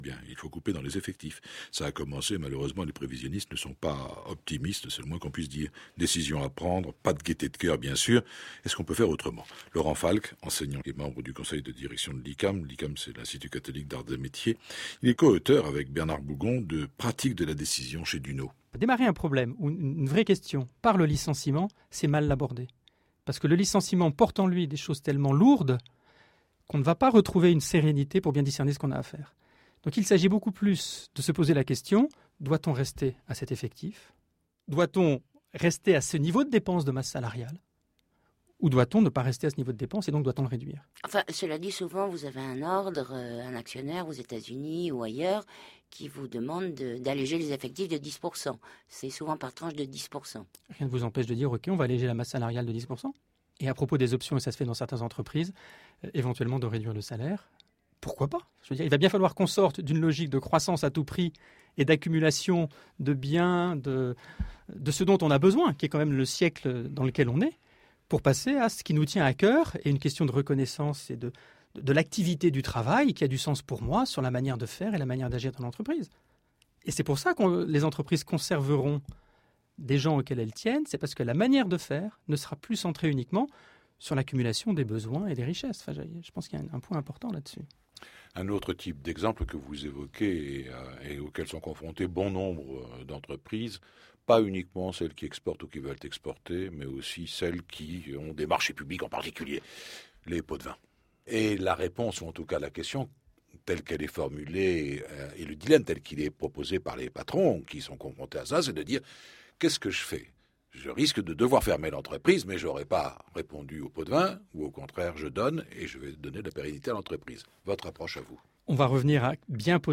De nombreux sujets intéressants sont abordés avec des spécialistes, des personnes de terrain. J’apprécie ce type de documentaire radiophonique.